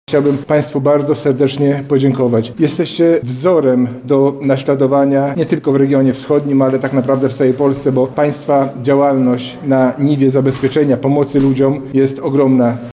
Dziś Wicewojewoda Lubelski Robert Gmitruczuk podziękował operatorom z Lublina za ich pracę: